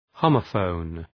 Προφορά
{‘həʋmə,fəʋn}